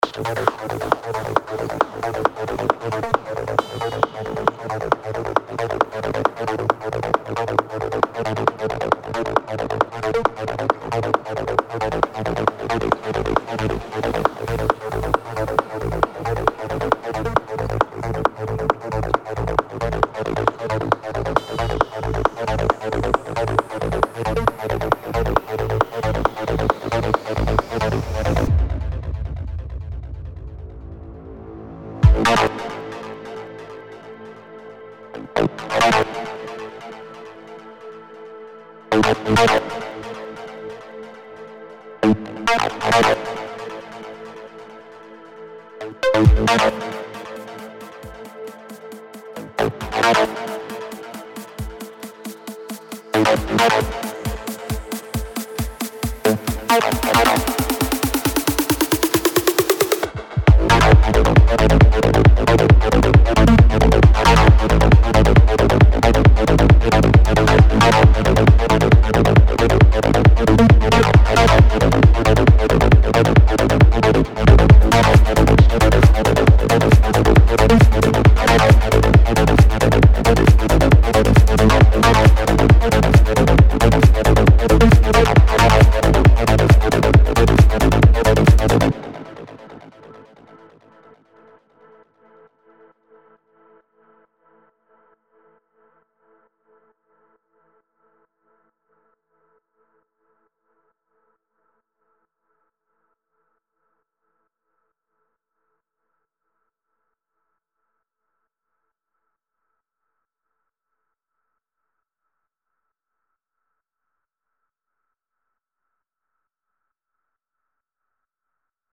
הכנתי טראק בסגנון טכנו